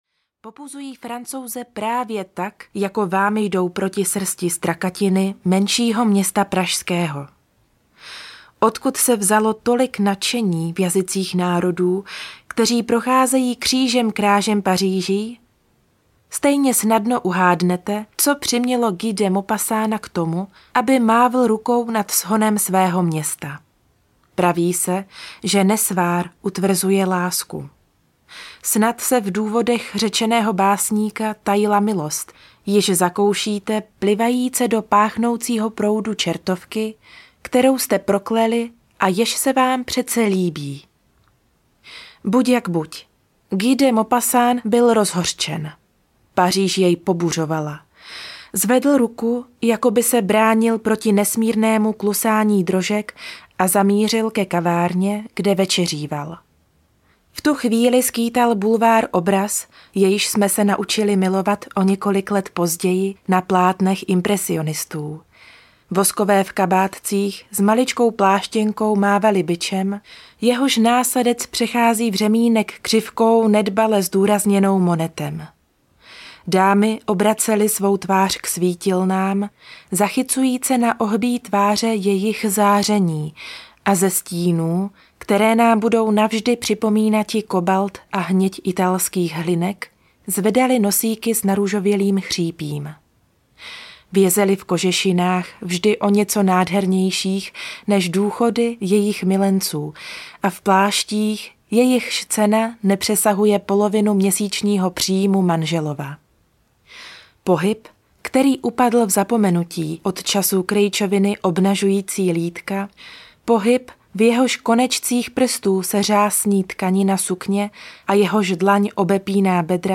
Luk královny Dorotky audiokniha
Ukázka z knihy